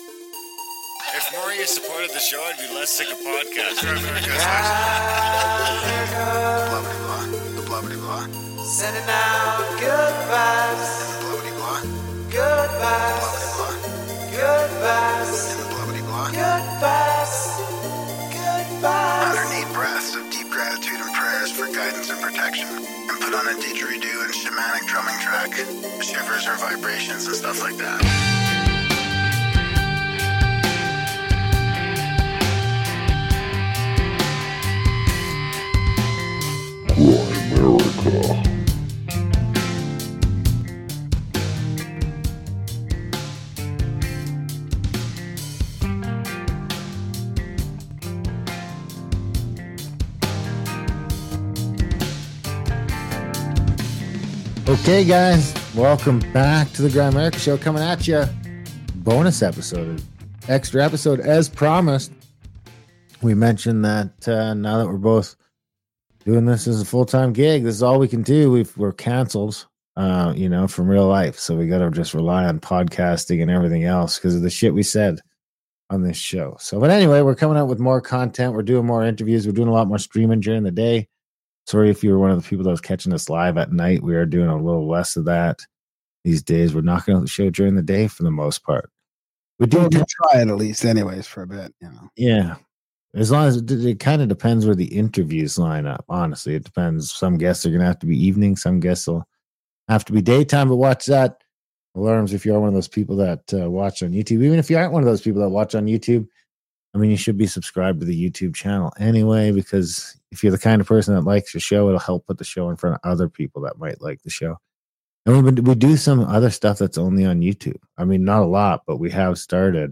Interview starts at 14:36